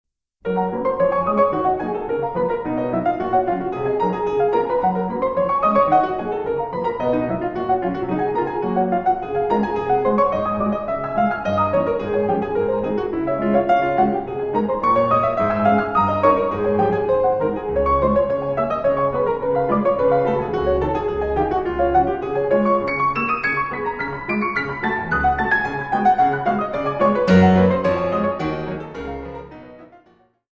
piano.
Classical, Keyboard